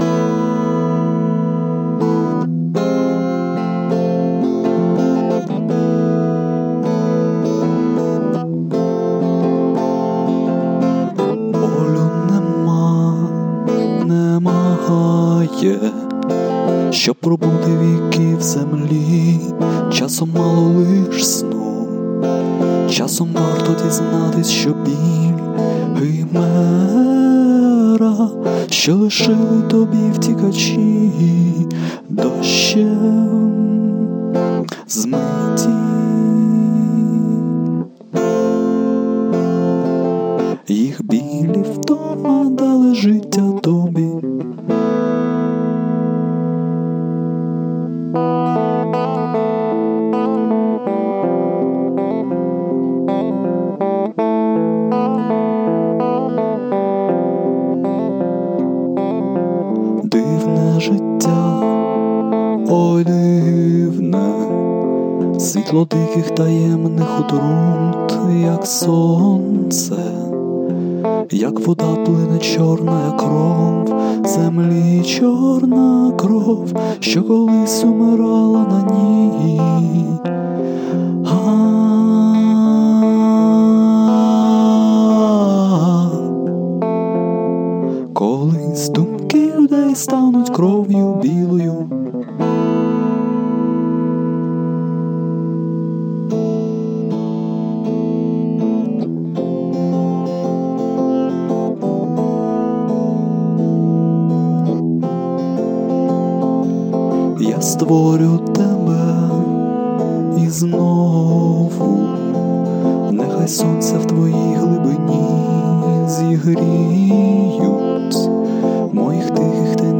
Живая запись четырех песен